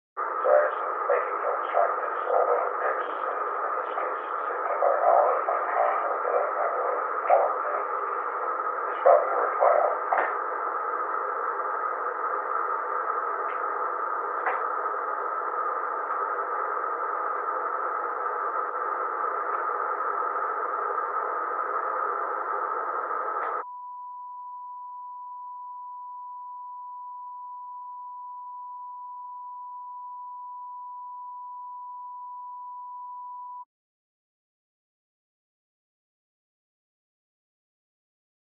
Location: Camp David Hard Wire
The President dictated a memorandum [?].
[Unintelligible]